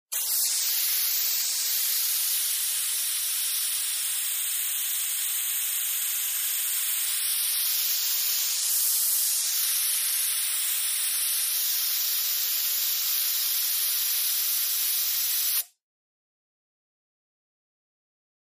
Air Compressor On, Steady Air Hiss, And Off